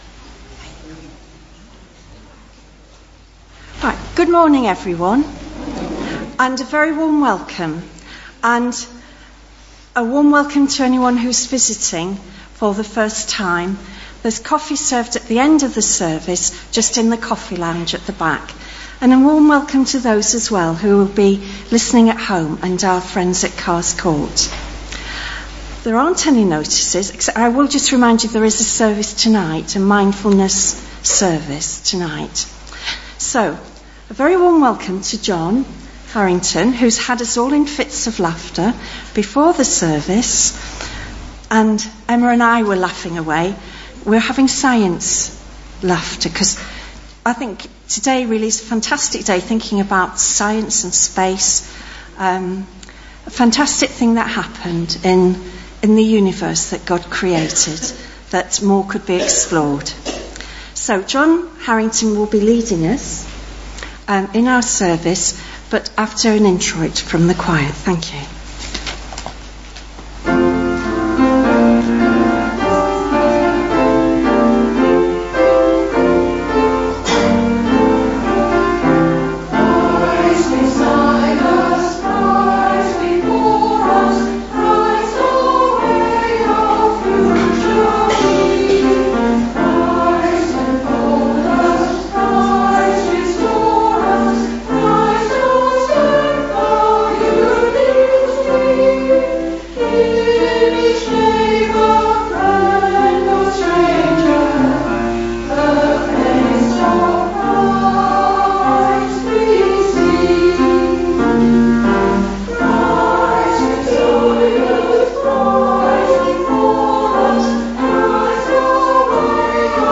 2019-07-21 Morning Worship
Genre: Speech.